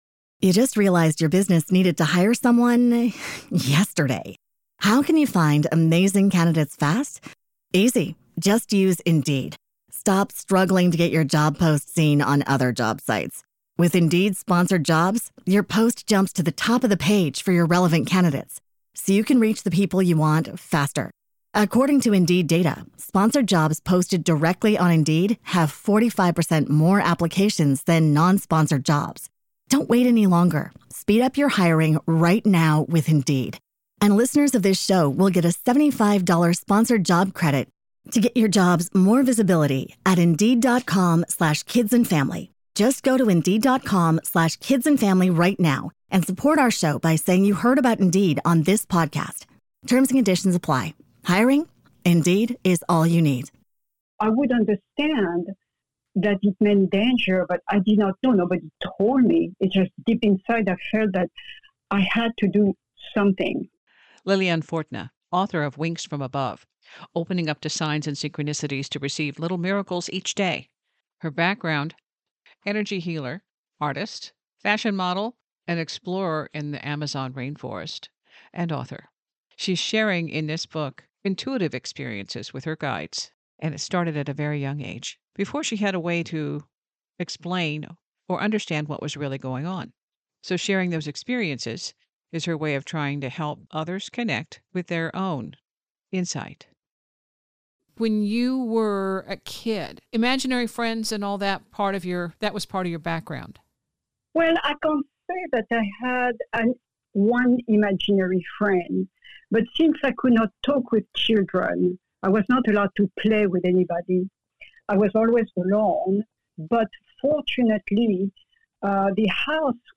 Later in the interview - appx. 50 min. - she shares the method she used to help her granddaughter clear her space of a stink bug.